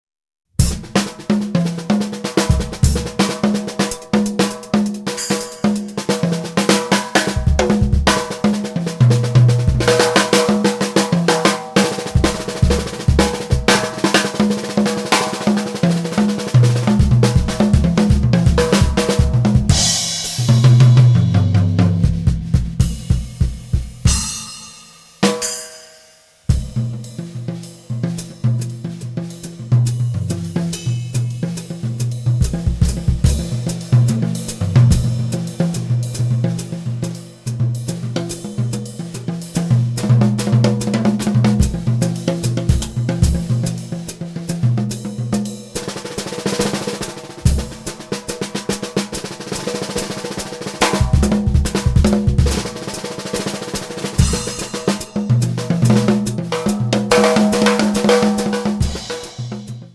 percussioni